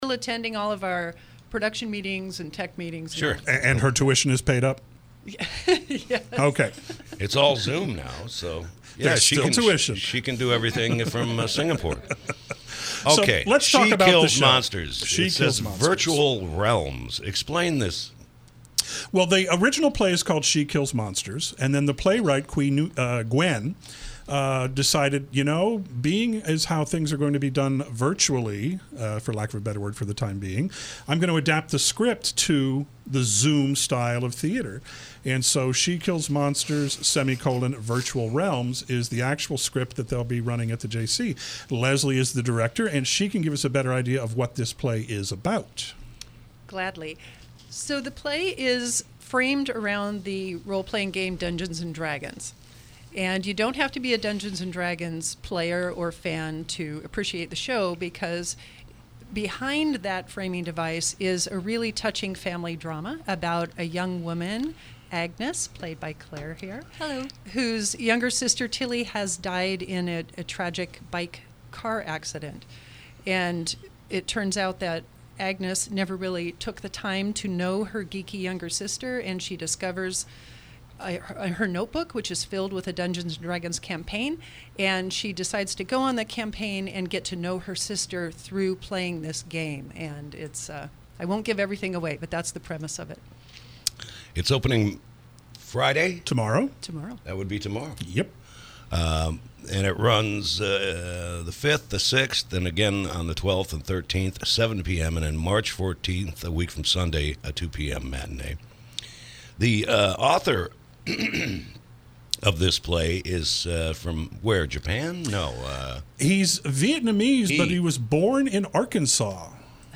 KSRO Interview – “She Kills Monsters”